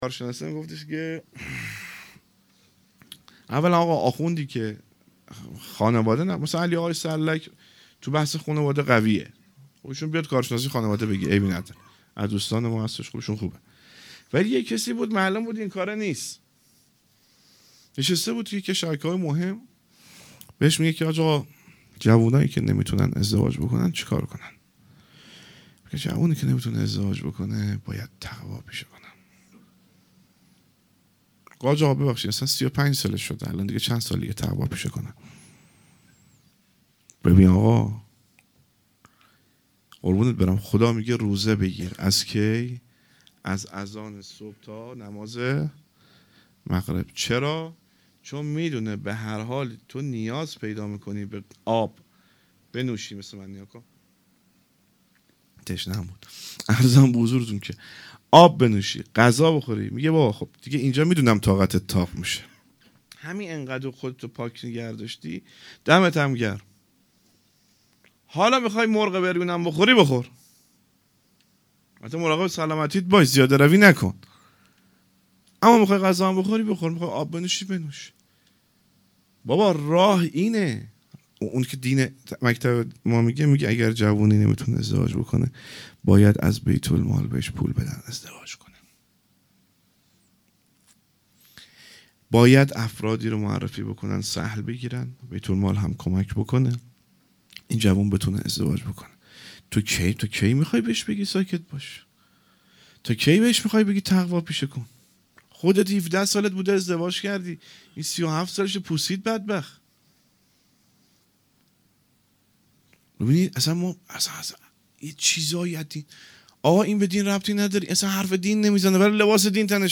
جشن ولادت حضرت علی اکبر علیه السلام